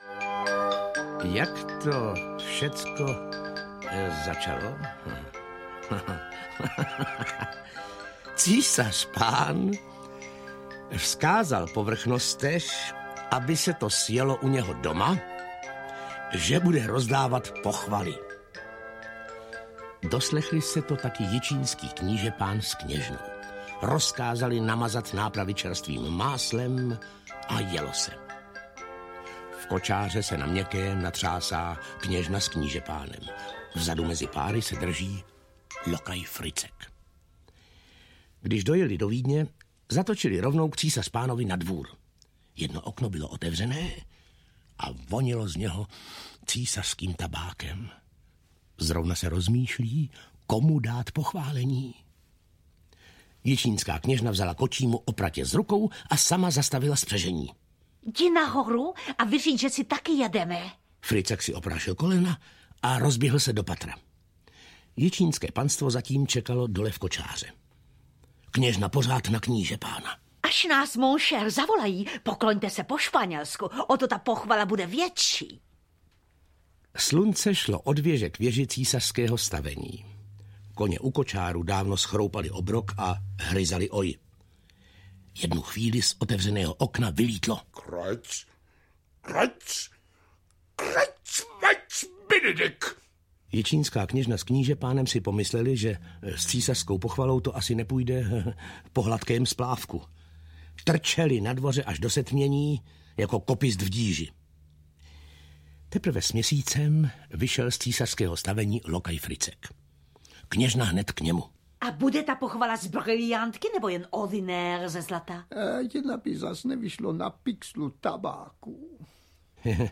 Jak ševci zvedli vojnu pro červenou sukni audiokniha
Ukázka z knihy
• InterpretVáclav Voska